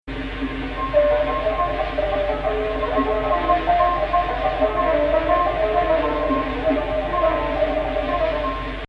Type: Suznak descending